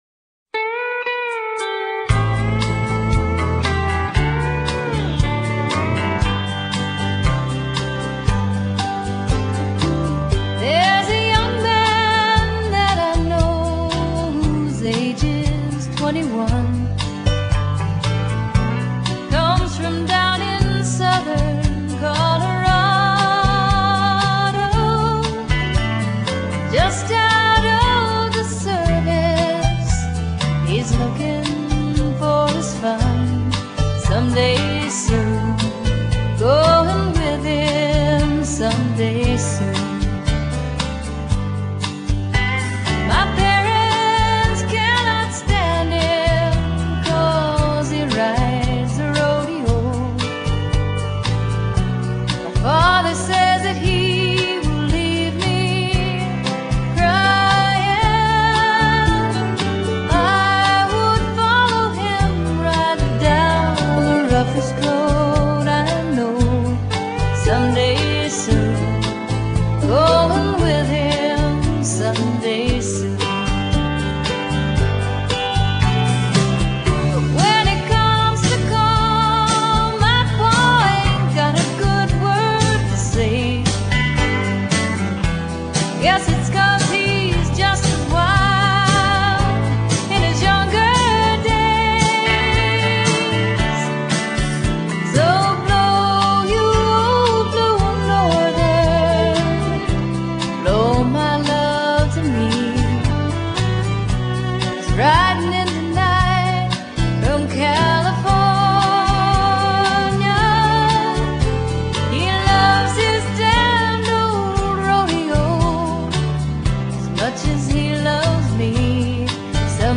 乡村音乐不像纯古典音乐離自己很遥远；也不像摇滚、重金属音乐那样嘈杂。